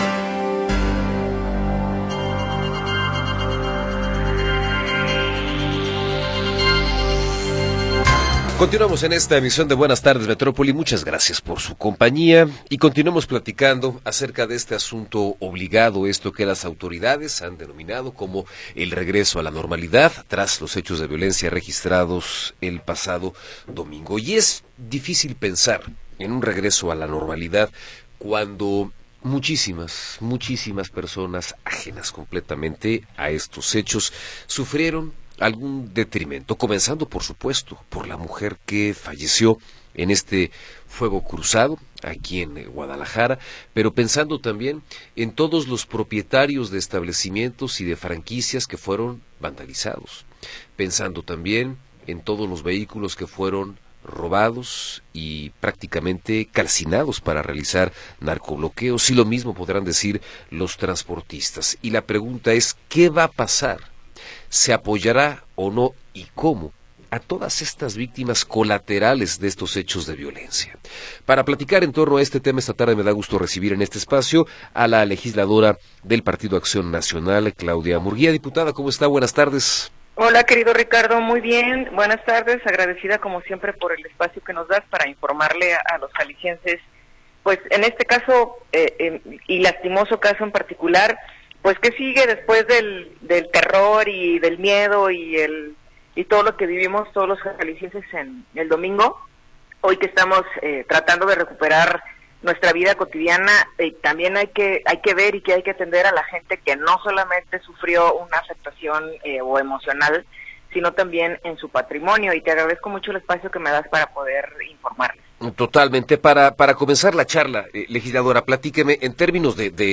Entrevista con Claudia Murguía Torres